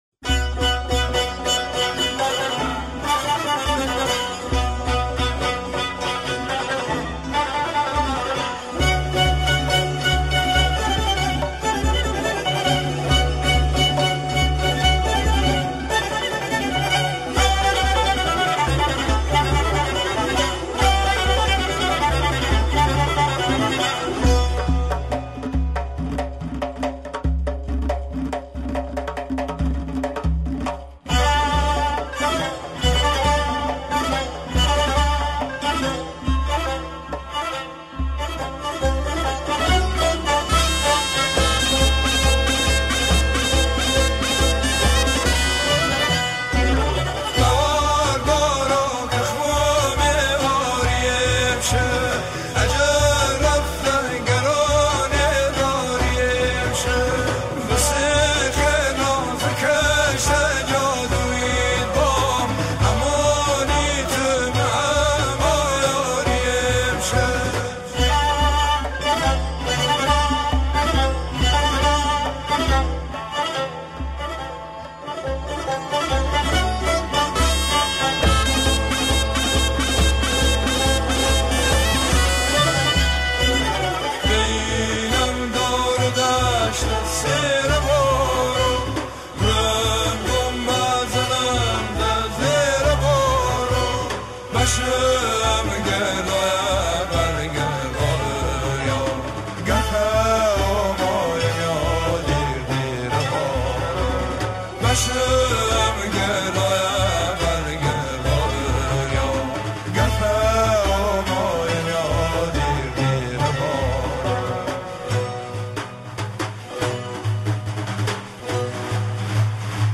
لری